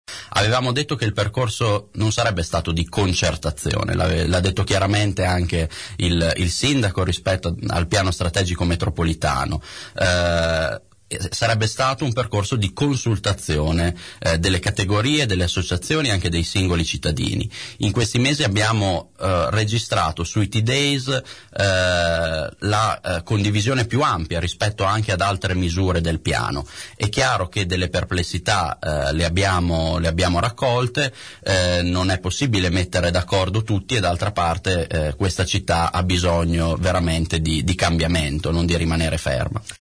L’assessore Andrea Colombo, ospite nei nostri studi il giorno dopo l’annuncio dei Tdays permanenti nei fine settimana e nei giorni festivi, spiega il metodo adottato dall’amministrazione su questa decisione.